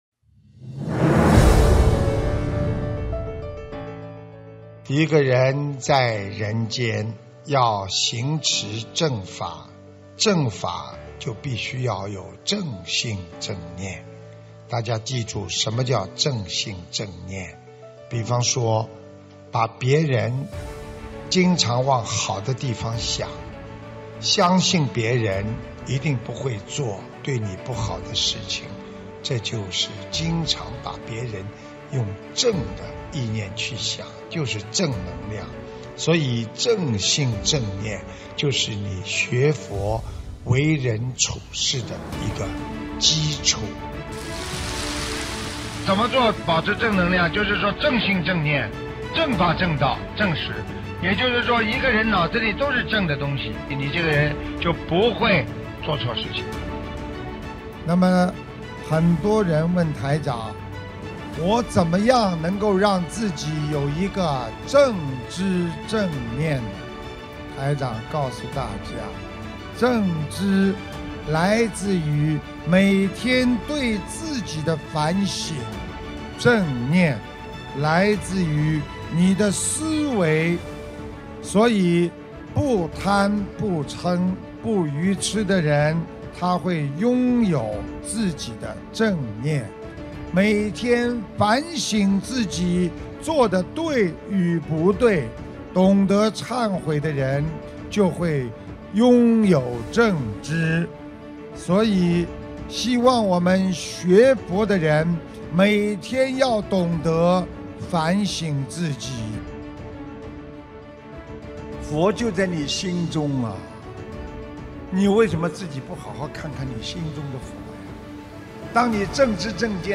首页 >>法会开示 >> 震撼视频